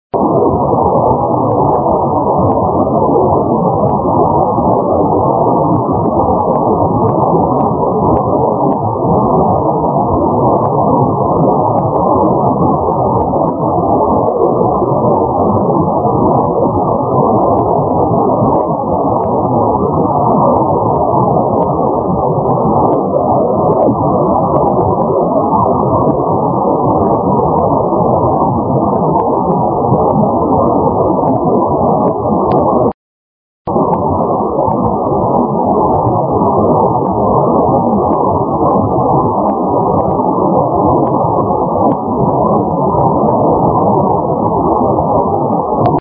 すべて名古屋市内の自宅での音なので人工ノイズ付です。（HI!)
今回も良い音にならなかった。空耳レベルで厳しい音でした。